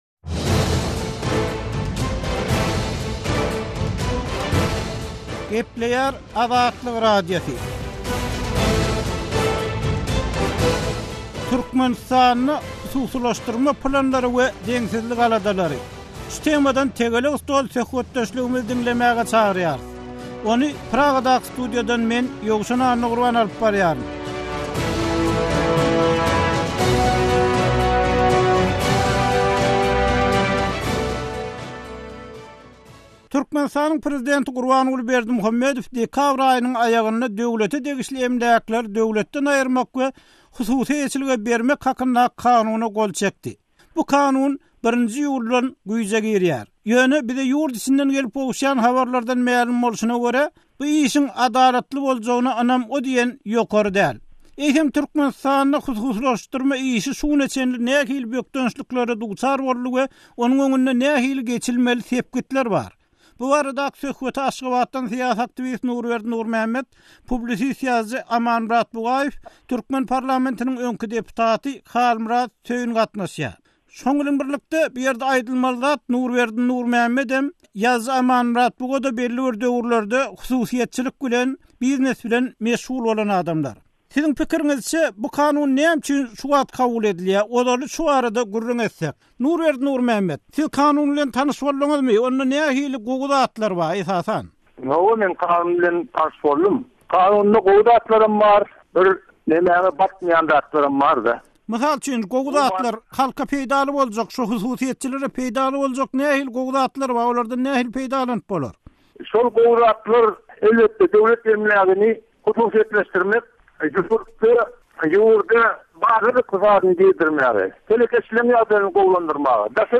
Azatlyk Radiosynyň bu tema bagyşlap guran “Tegelek stol” söhbetdeşligini diňläp, pikir-bellikleriňizi aýtsaňyz, hoşal bolarys.